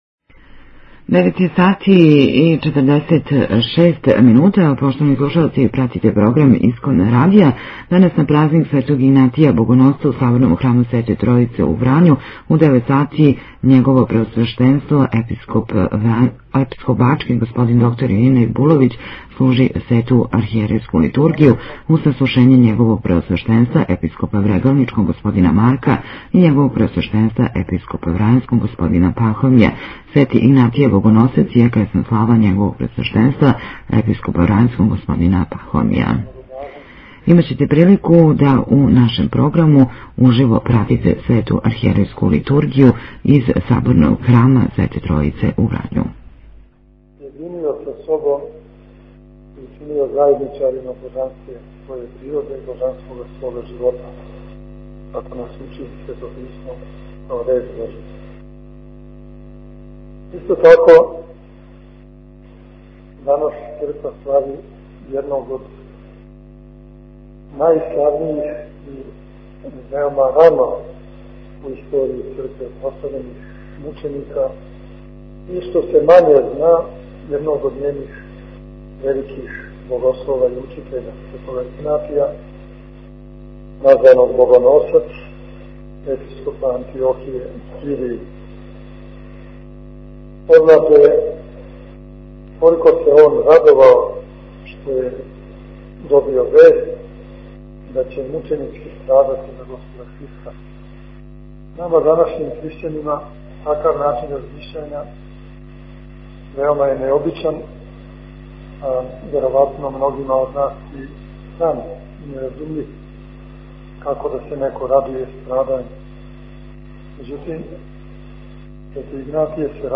Звучни запис беседе Епископа бачког Господина др Иринеја
beseda-vranje.mp3